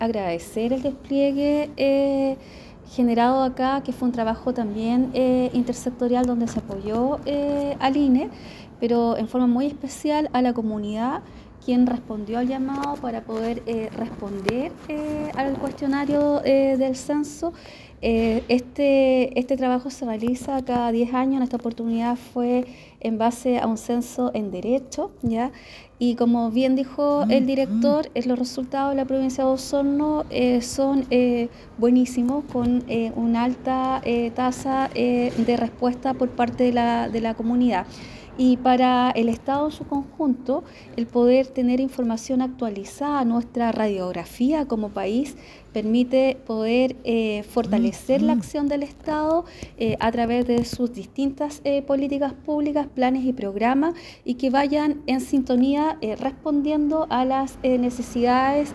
Por su parte, la Delegada Presidencial Provincial, Claudia Pailalef agradeció a la comunidad por su colaboración, enfatizando que estos datos contribuyen a mejorar las políticas públicas para la ciudadanía.